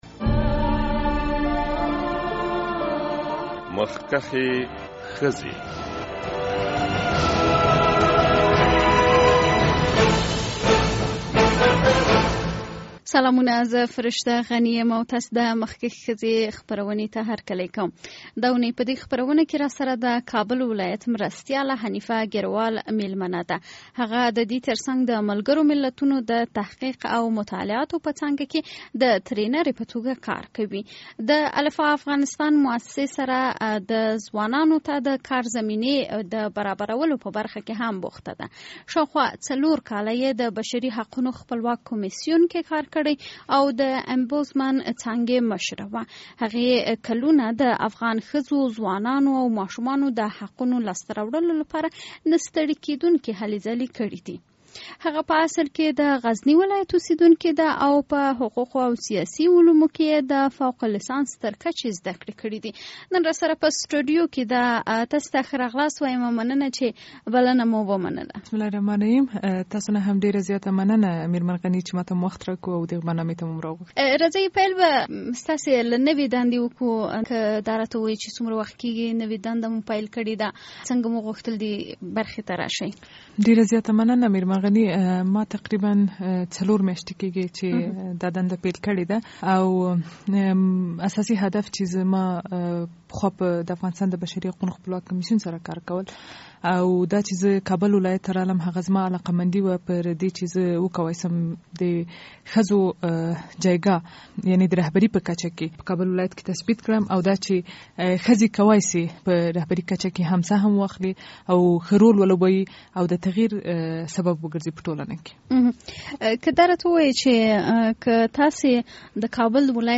دا اوونۍ د مخکښې ښځې په خپرونه کې مو د کابل ولایت له مرستیالې حنیفه ګېرو وال سره خبرې کړي دي.